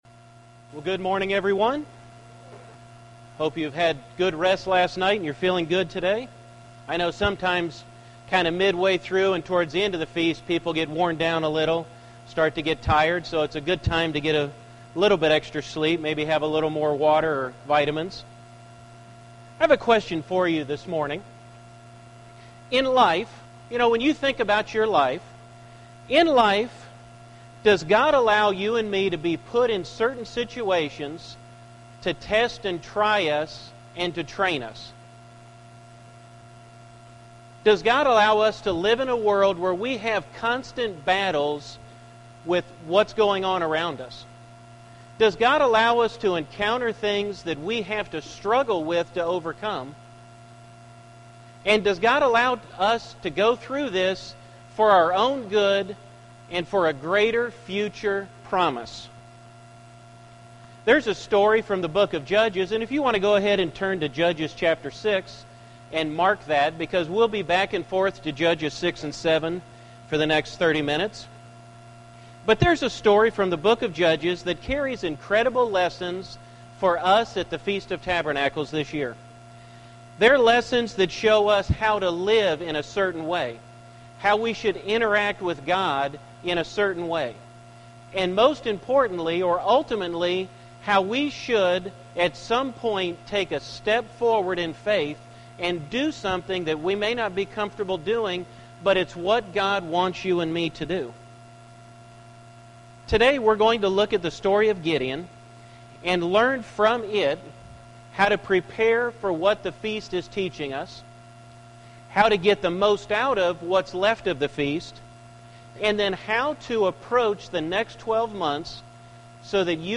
This sermon was given at the Oceanside, California 2013 Feast site.